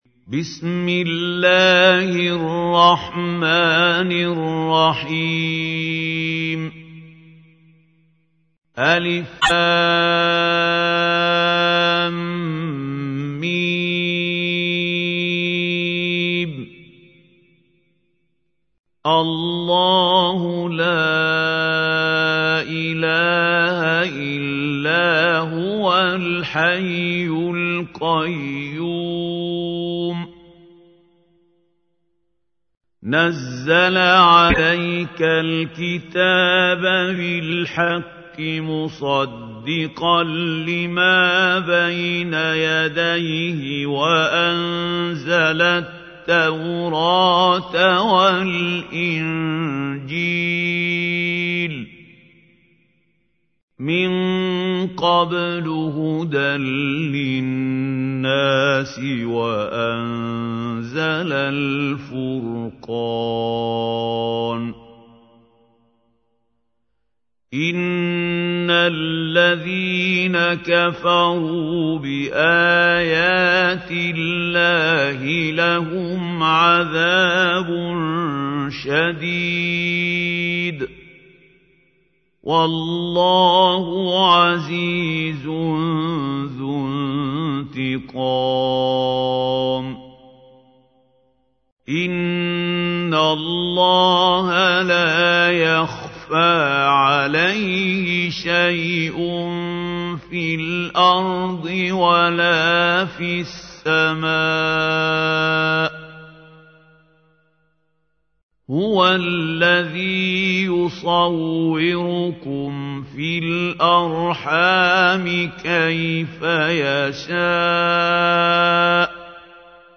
تحميل : 3. سورة آل عمران / القارئ محمود خليل الحصري / القرآن الكريم / موقع يا حسين